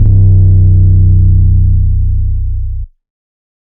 808s
DIST7M808.wav